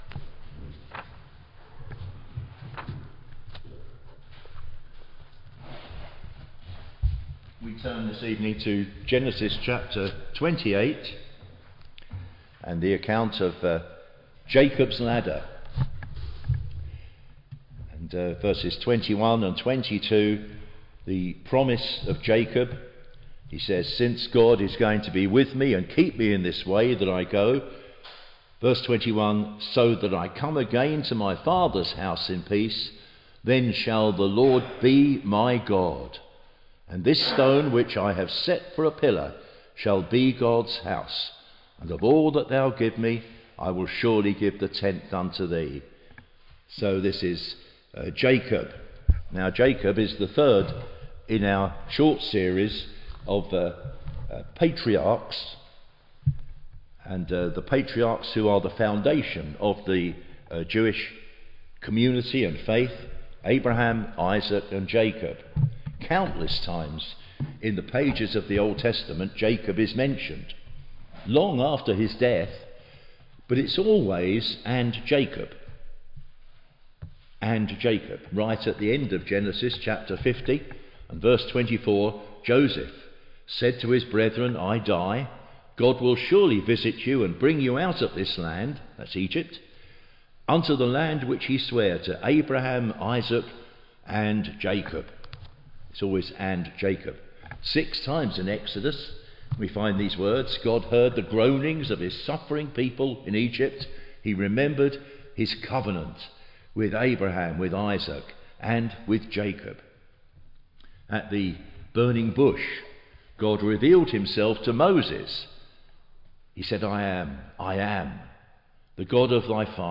Teaching and Gospel sermons on Genesis